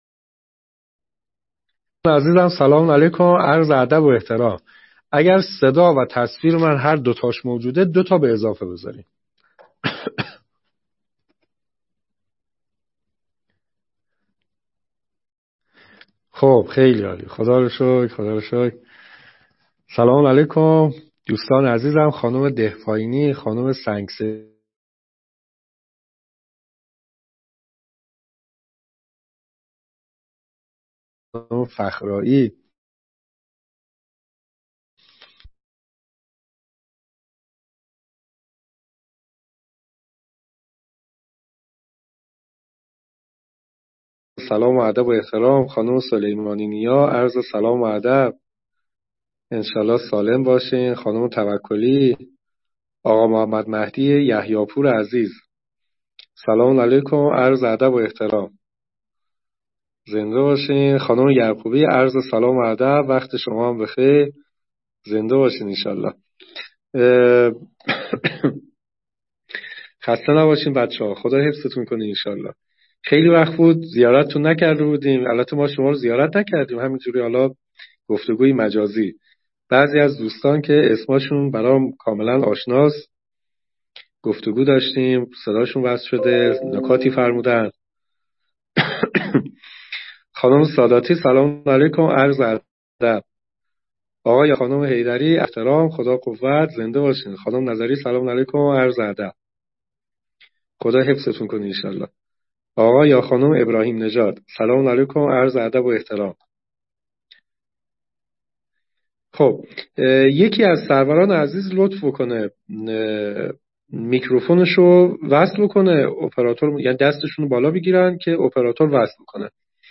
مقدمه تا بی نهایت - جلسه-پرسش-و-پاسخ_مبحث-خداشناسی